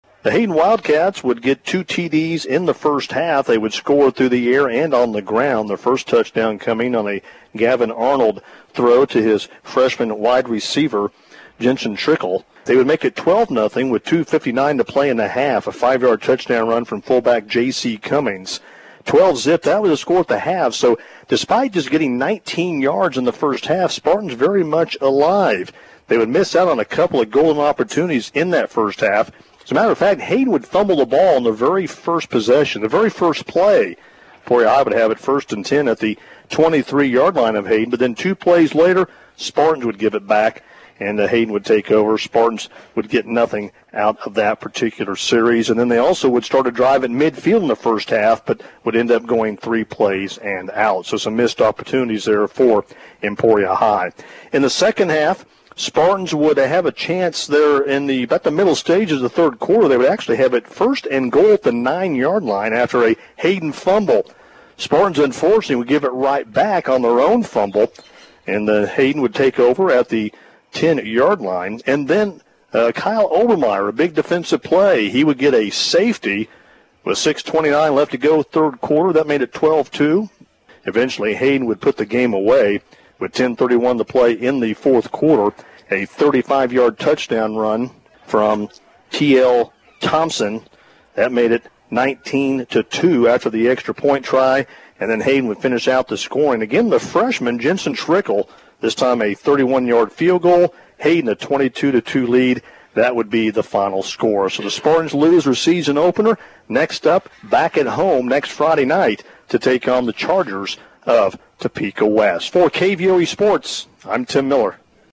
game report